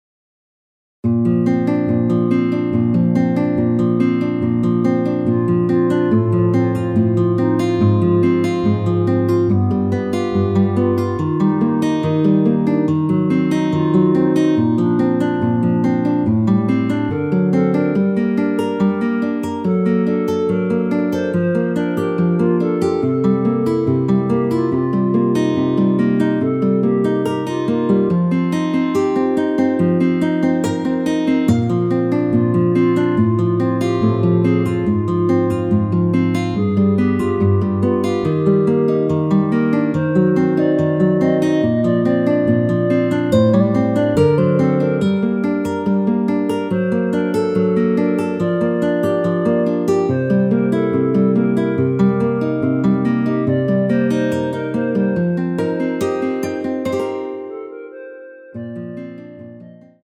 원키에서(+1)올린 멜로디 포함된 MR입니다.(미리듣기 확인)
Bb
앞부분30초, 뒷부분30초씩 편집해서 올려 드리고 있습니다.